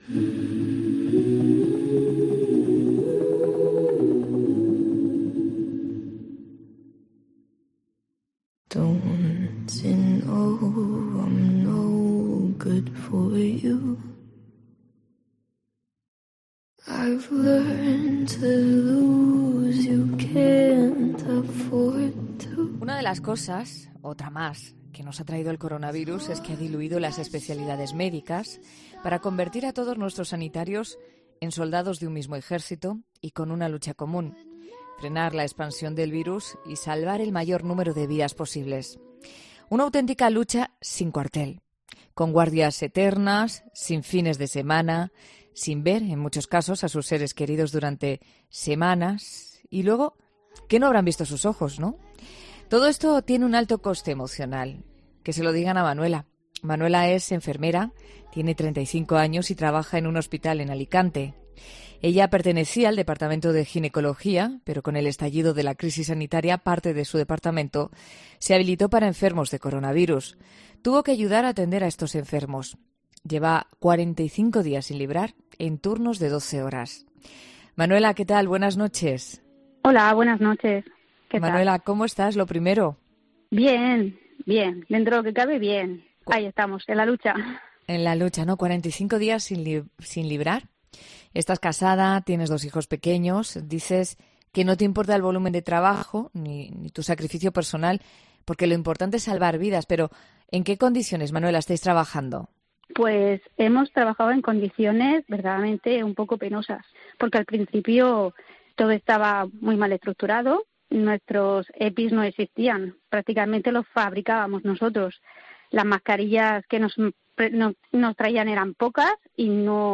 'La Noche' de COPE es un programa que mira la actualidad de cada día con ojos curiosos e inquietos, y en el que el tema principal de cada día, aquel del que todo el mundo habla, se ve desde un punto de vista distinto.